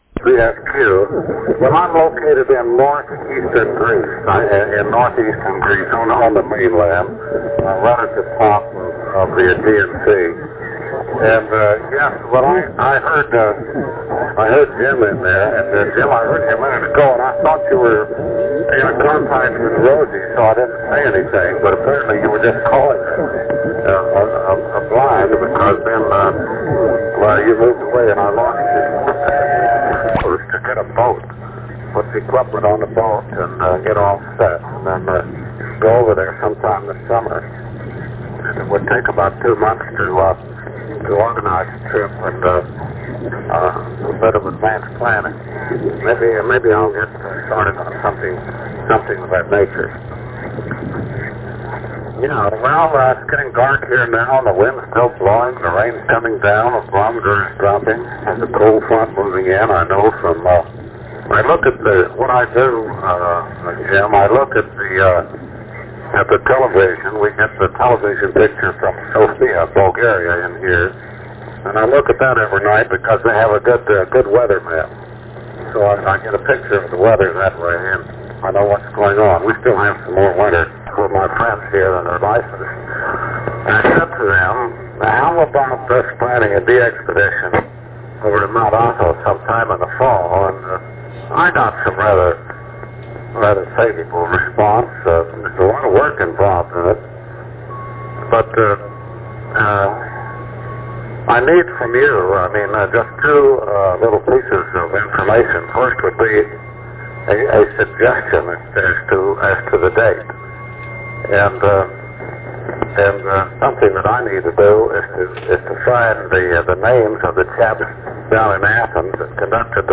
on 20 Meter SSB Longpath from California